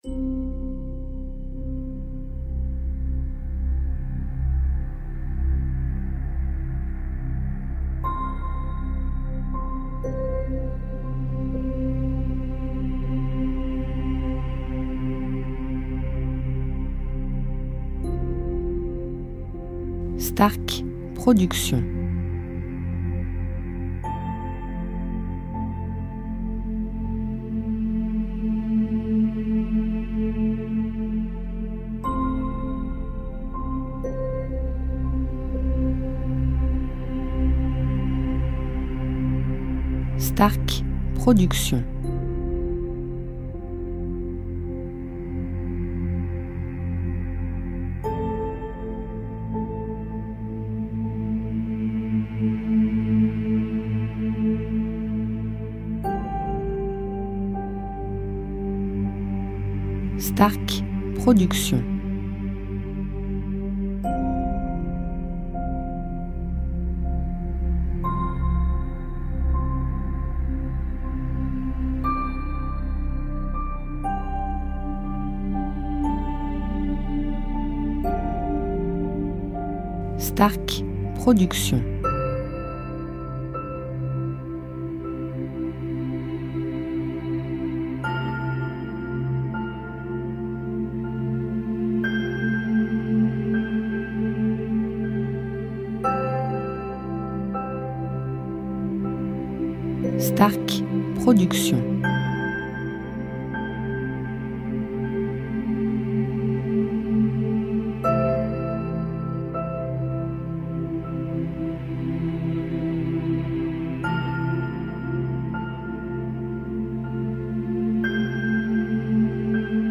style Sophrologie Méditation